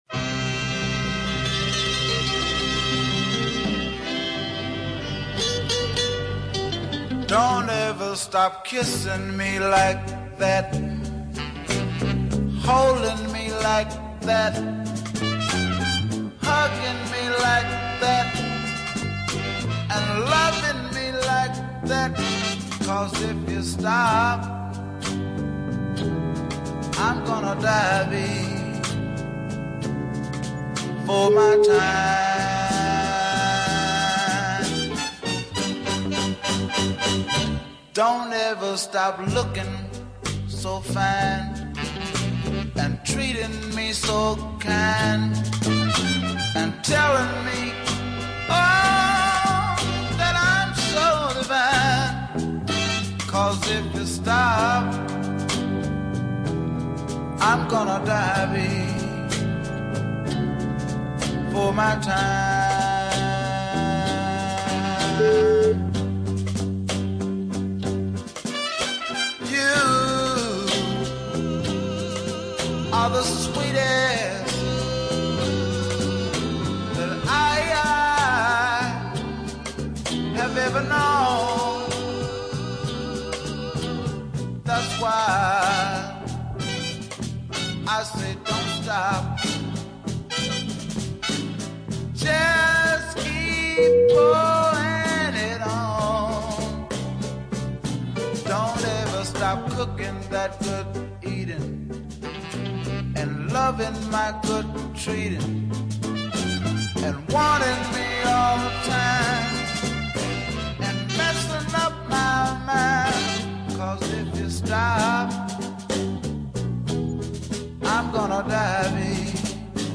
West Coast blues and R & B vocalists
slow doo wop influenced R & B